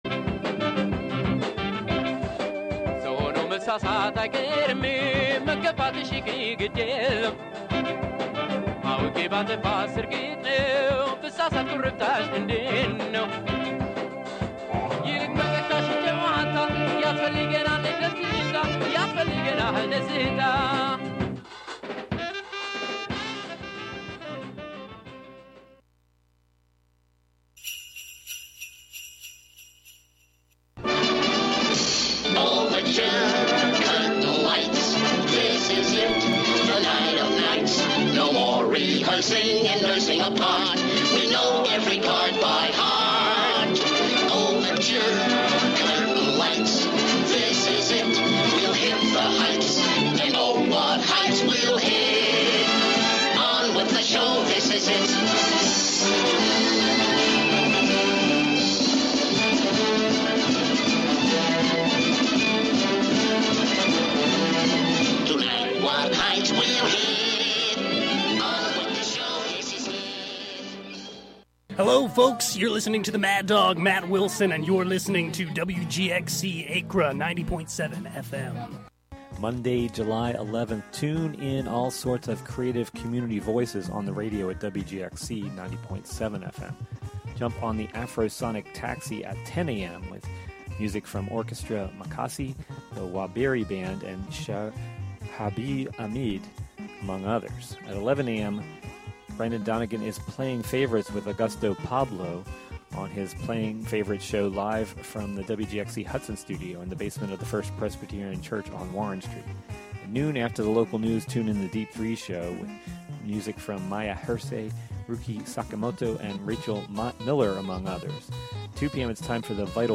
who used the melodica